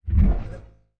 Robotic Servo Notifcation 3 Bassy.wav